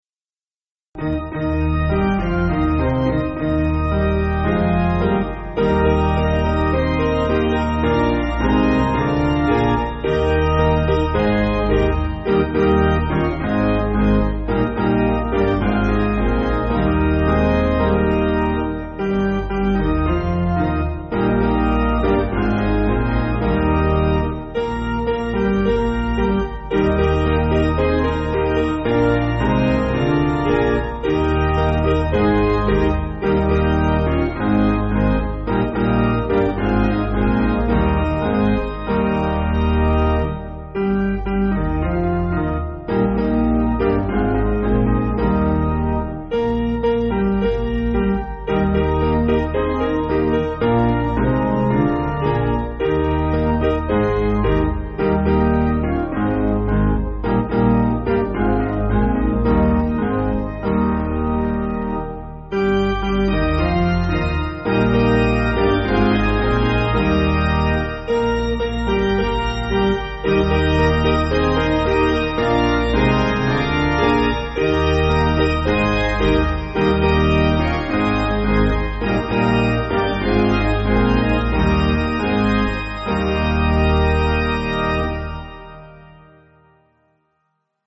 African-American Spiritual
Basic Piano & Organ